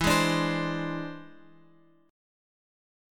E+7 chord {x 7 6 7 x 8} chord
E-Augmented 7th-E-x,7,6,7,x,8.m4a